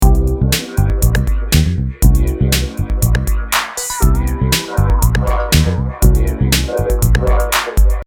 The initial loop.
oldloop.mp3